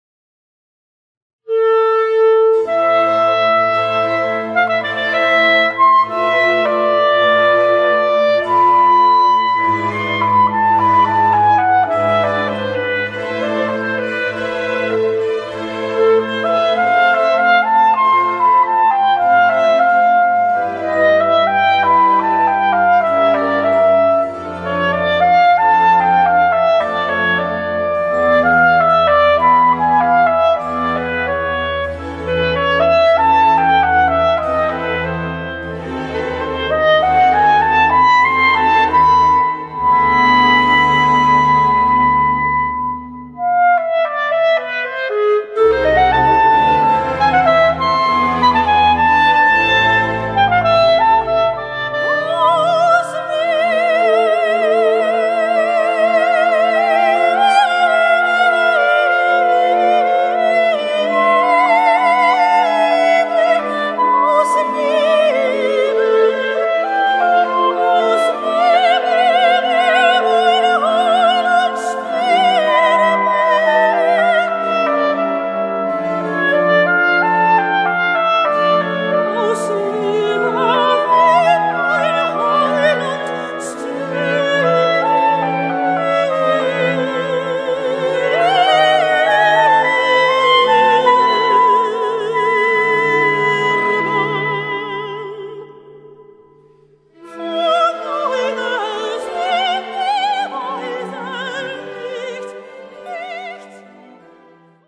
Voicing: Soprano, Clarinet and Orchestra